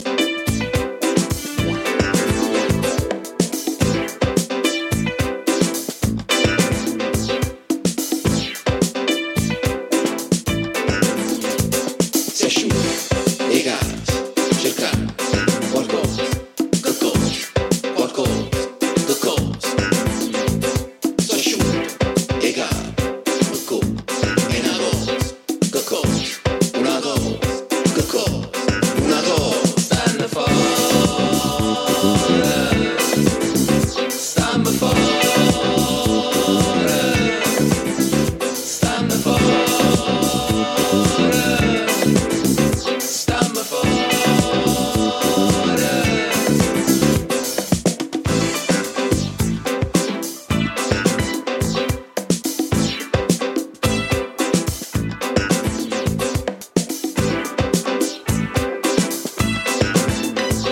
disco, jazz-funk, African rhythms